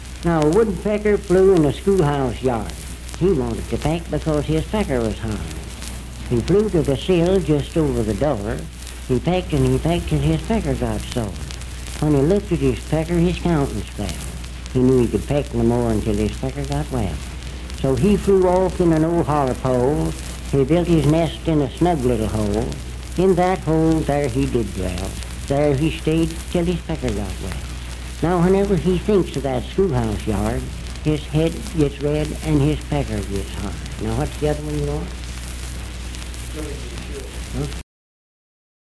Unaccompanied vocal music
Performed in Sandyville, Jackson County, WV.
Folklore--Non Musical, Bawdy Songs
Voice (sung)